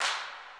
Have Mercy Clap.wav